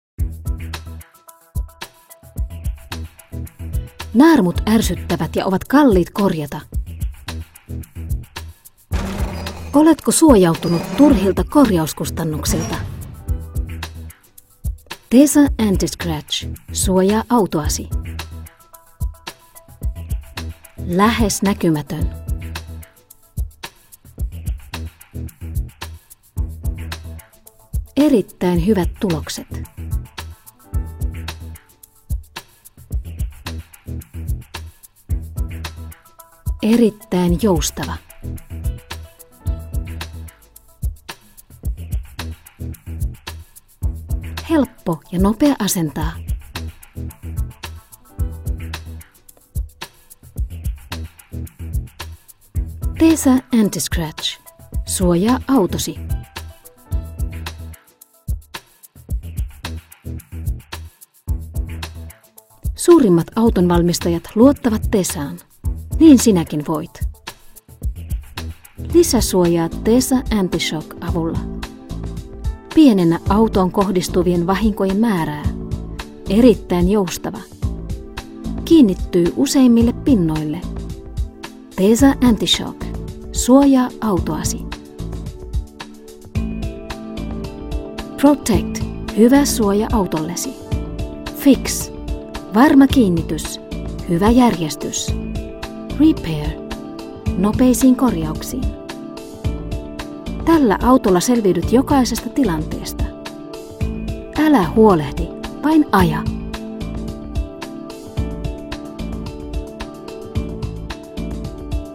Sprechprobe: Werbung (Muttersprache):
tesaAUTO Finnland.mp3